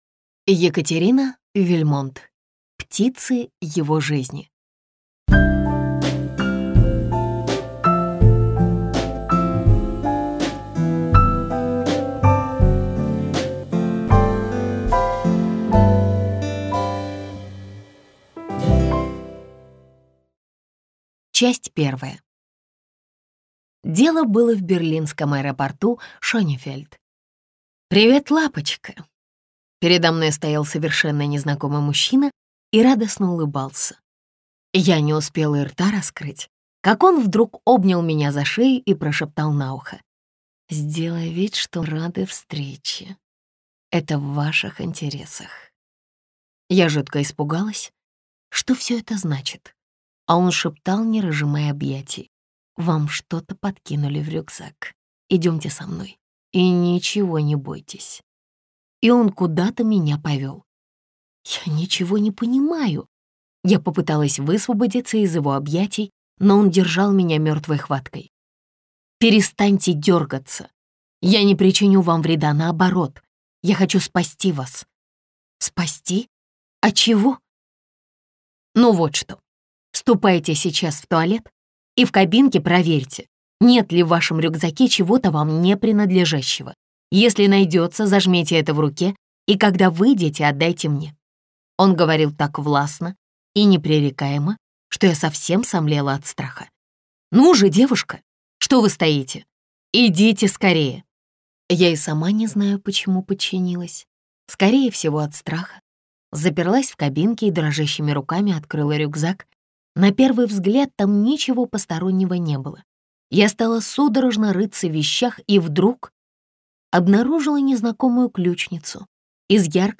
Аудиокнига Птицы его жизни - купить, скачать и слушать онлайн | КнигоПоиск